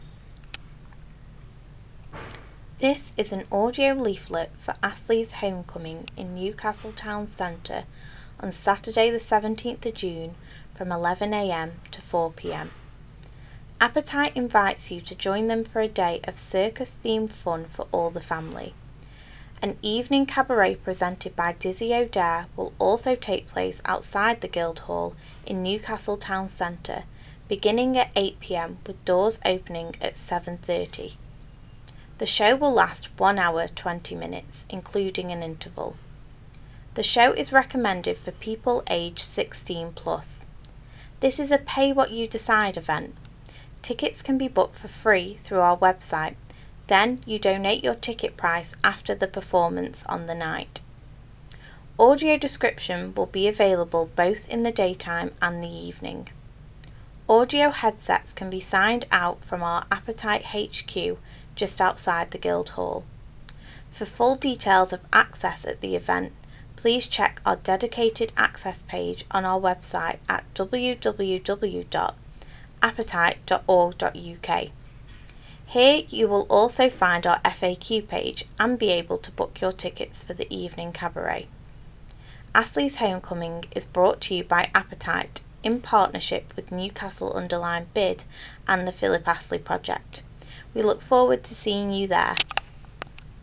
Astleys-Homecoming-Audio-Leaflet.wav